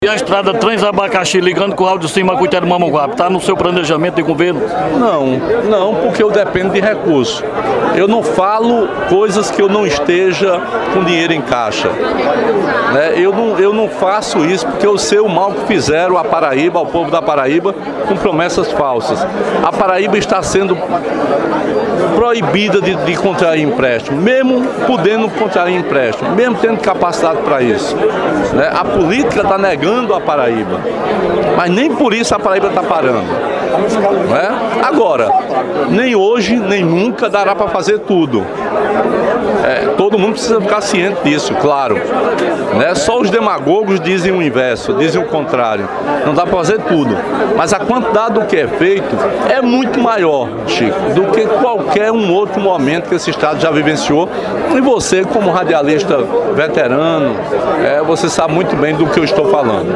Na última terça-feira (28) em visita a região do vale do Mamanguape, em Rio Tinto, o governador Ricardo Coutinho (PSB)